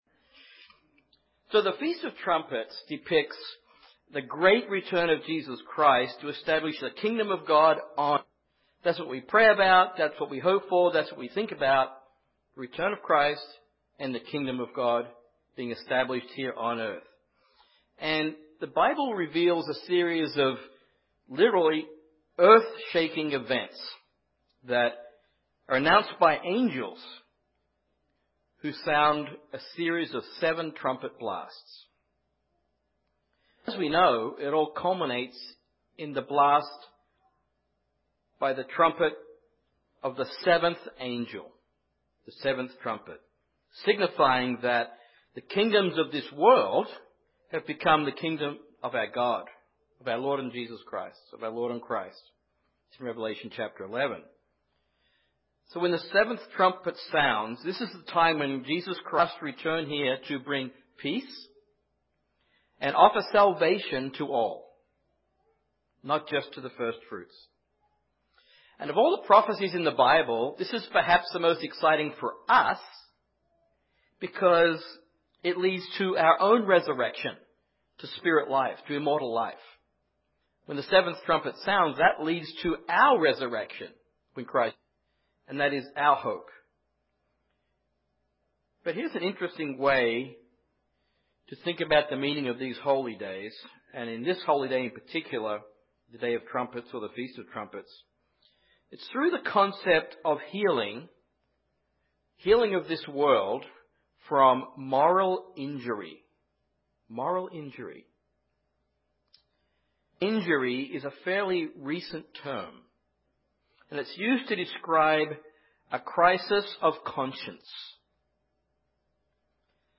This sermon looks at the Feast of Trumpets through the concept of healing of this world from moral injury—from doing something against your moral code.